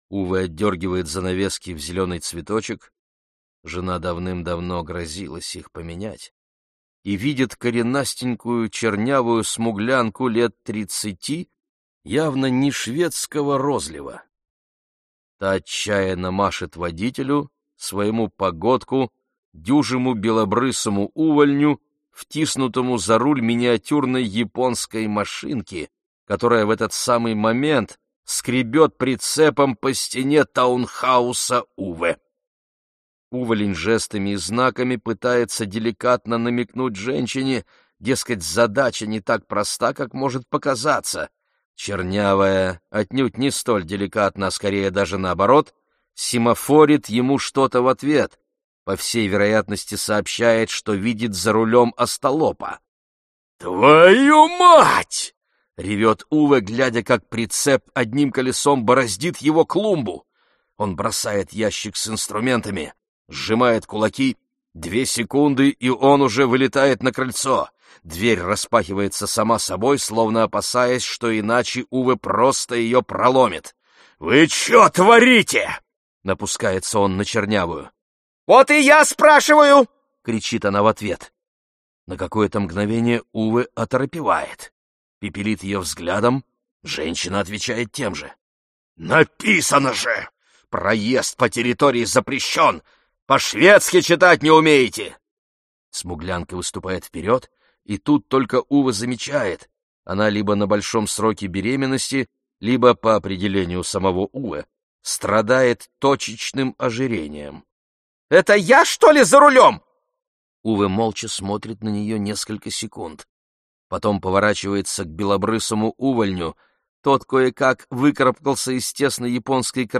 Аудиокнига Вторая жизнь Уве - купить, скачать и слушать онлайн | КнигоПоиск